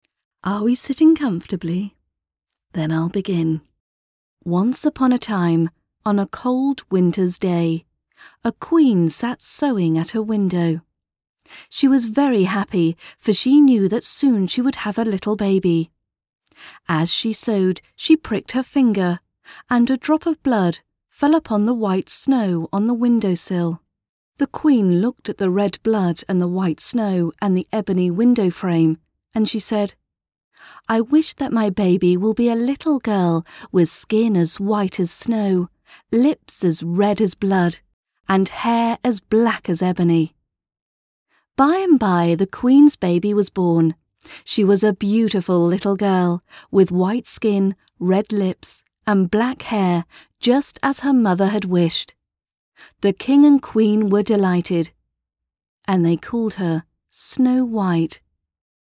englische Profi-Sprecherin mit britischem Akzent.
Sprechprobe: Werbung (Muttersprache):
Female Voiceover Artist with native British Accent.